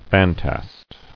[fan·tast]